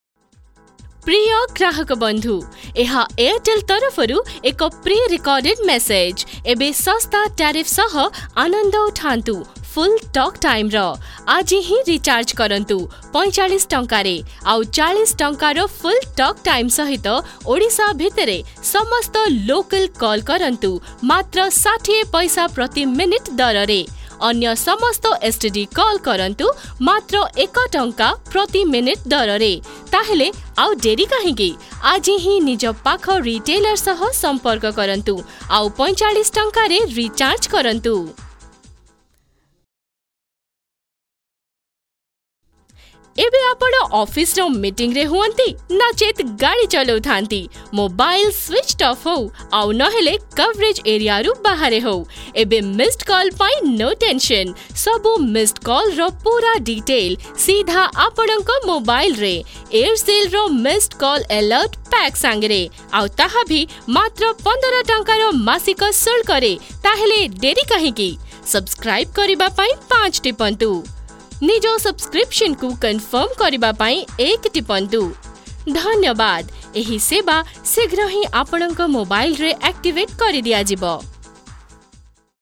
Oriya Voice Over Sample
Oriya Voice Over Female Artist -1